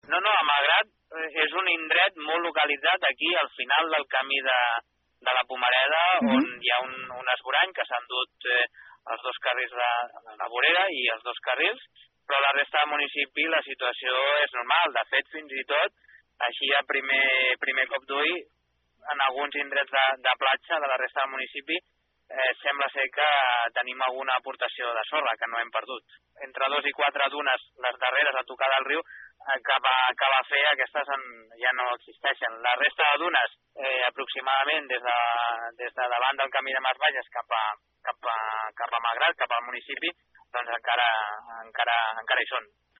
Óliver Sanchez-Camacho és el regidor de medi ambient de Malgrat.